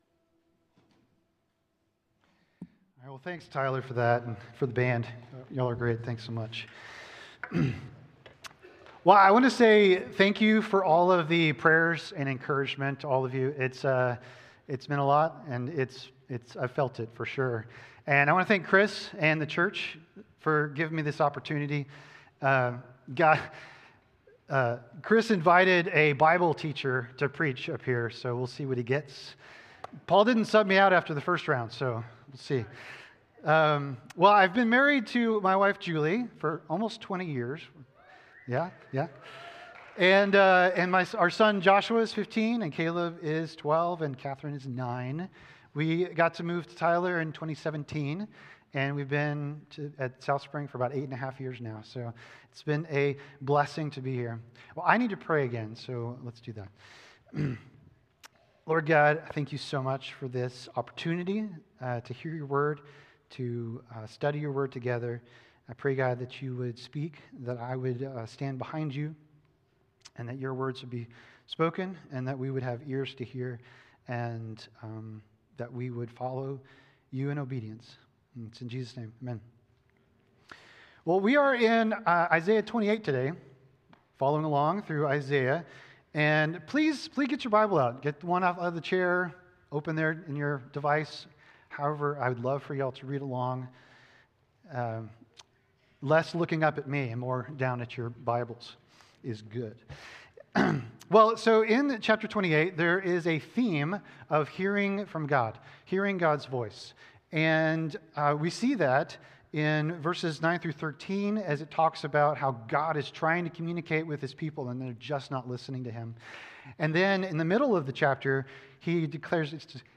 by South Spring Media | Apr 26, 2026 | 2026 Sermons, Isaiah, Isaiah Series | 0 comments